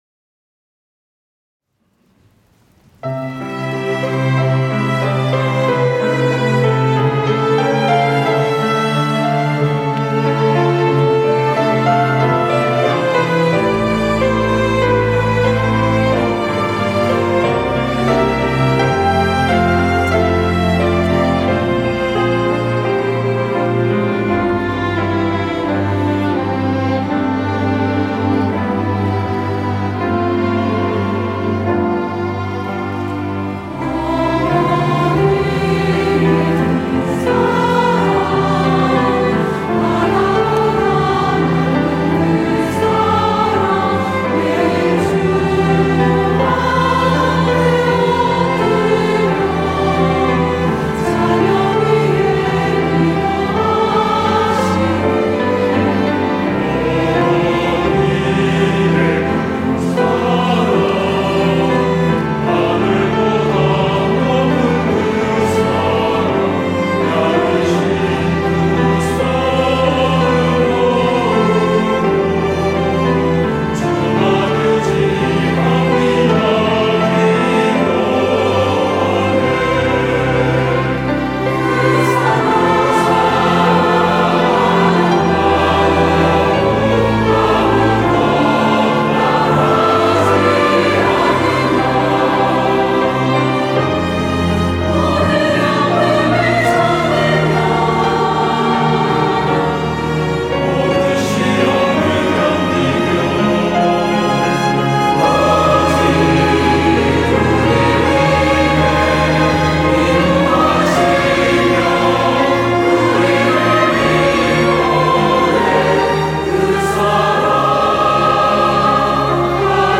호산나(주일3부) - 어머니의 기도
찬양대